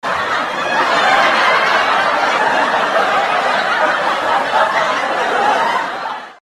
Звуки закадрового смеха